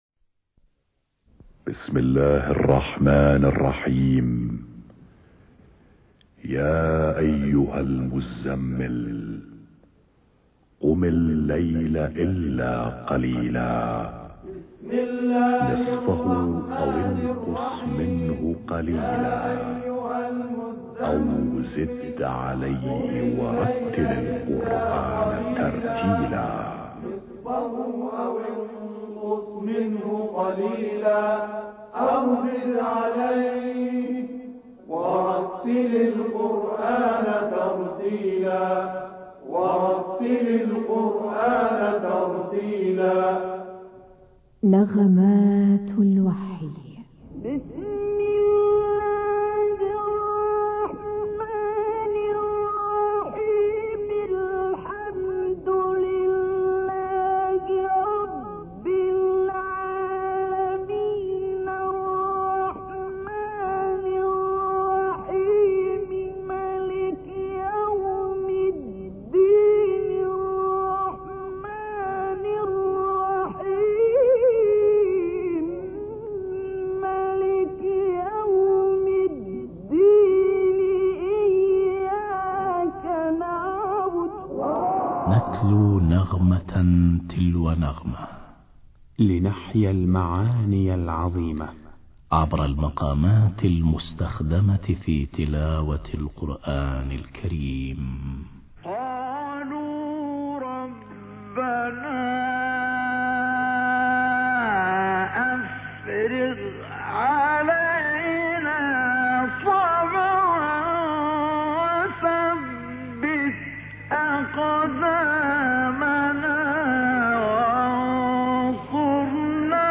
مقام الصبا